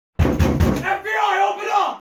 На этой странице собраны звуки, связанные с работой ФБР: перехваты переговоров, сигналы спецоборудования, тревожные гудки и другие эффекты.